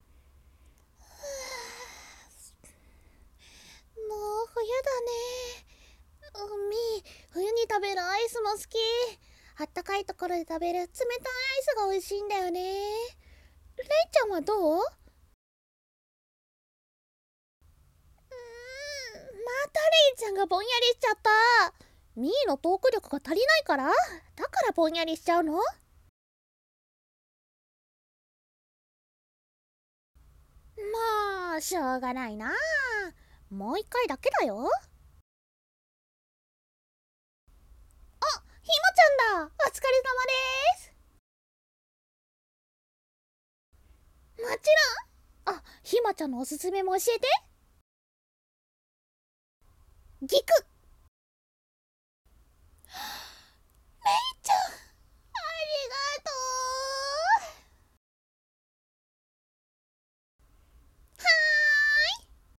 声劇①